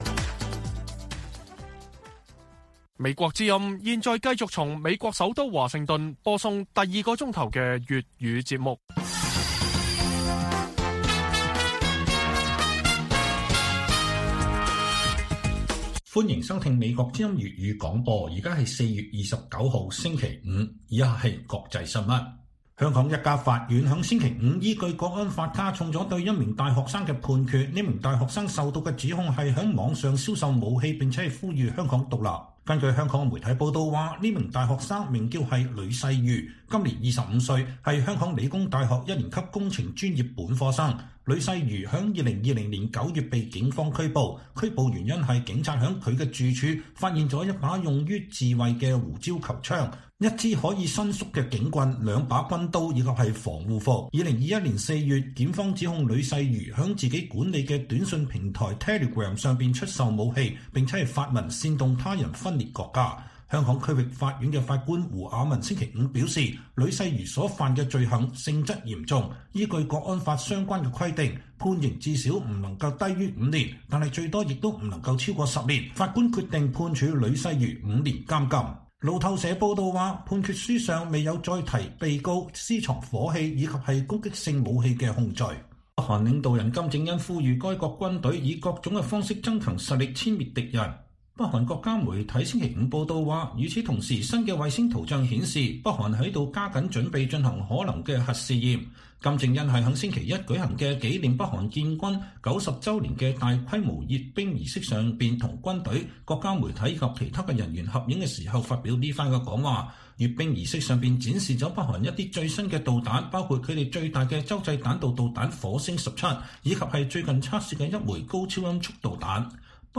粵語新聞 晚上10-11點: 香港理大學生因違反國安法被加重判決